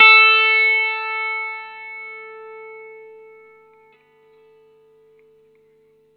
R12NOTE A +2.wav